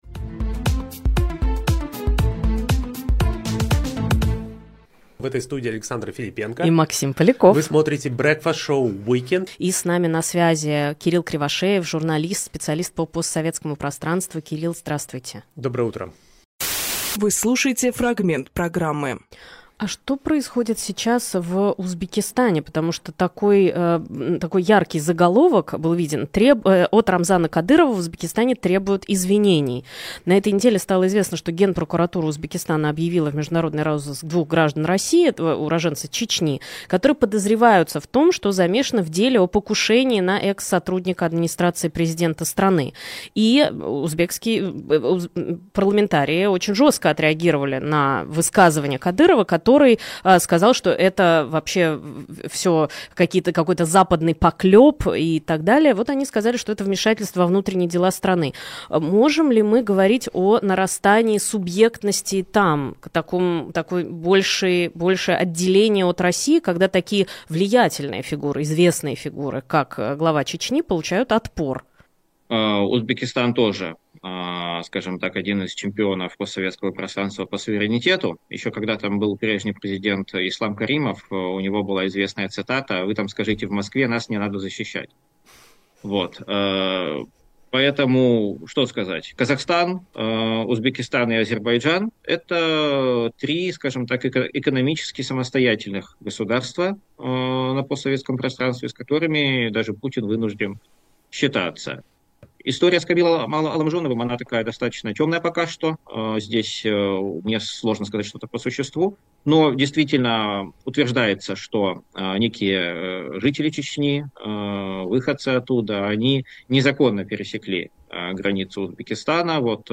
Фрагмент эфира от 29.12.24